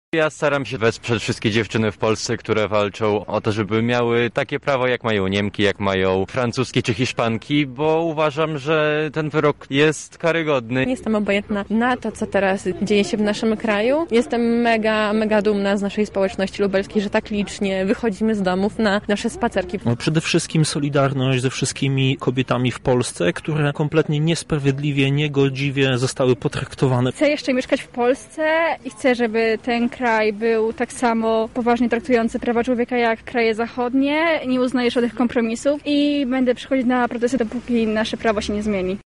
Zapytaliśmy uczestników o powody, dla których zdecydowali się na udział w blokadzie:
Sonda